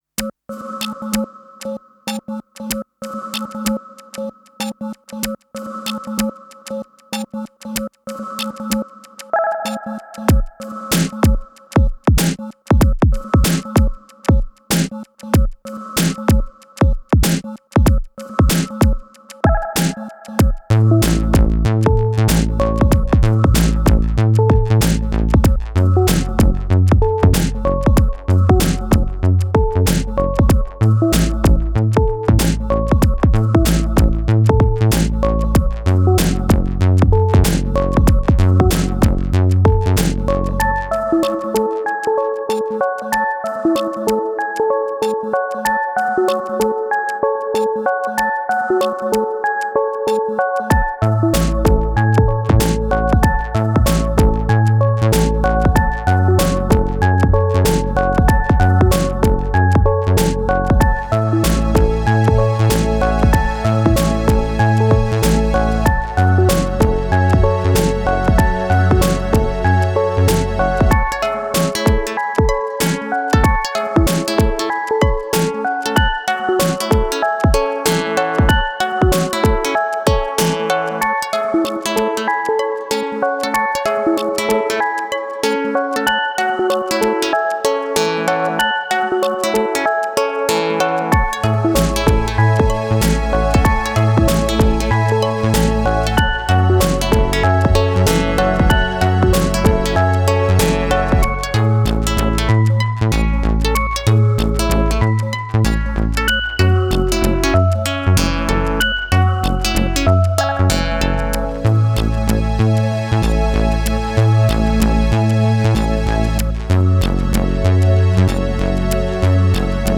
And this is just M8. Did it while lying on the couch: